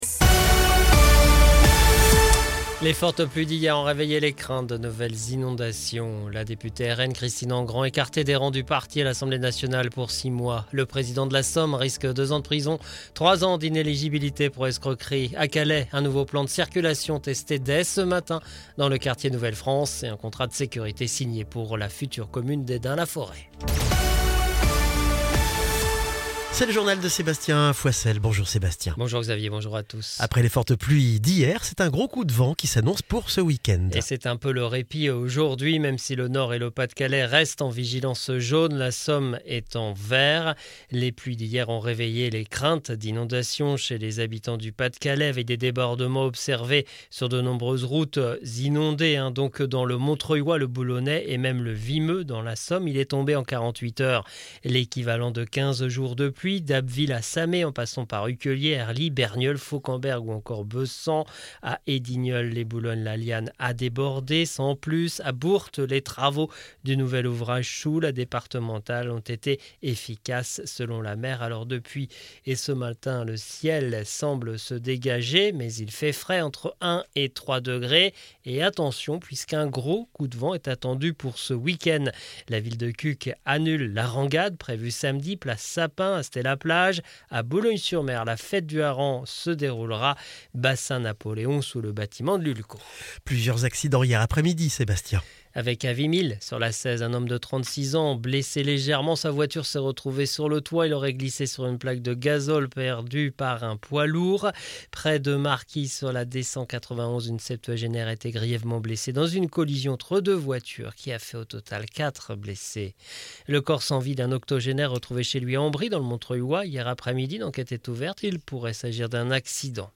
Le journal de ce mercredi 20 novembre 2024